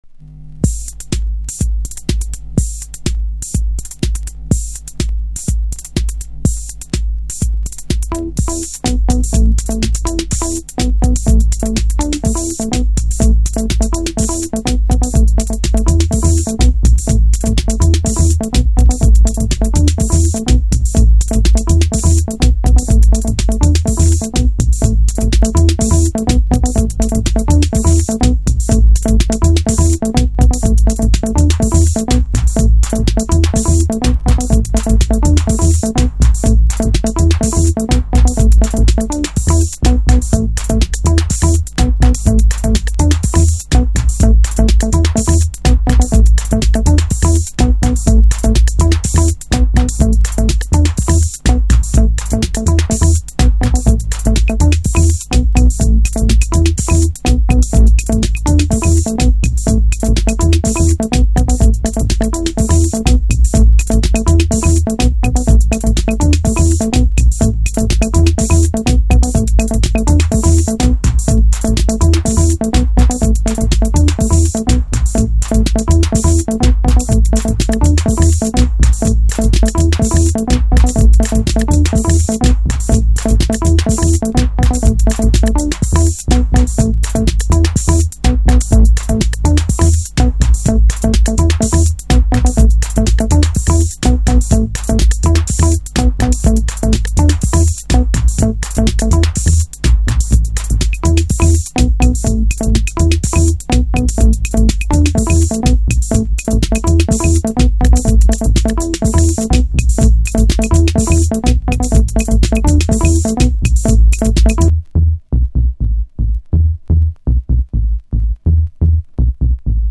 Early House / 90's Techno
ベースも素晴らしい鳴りをします。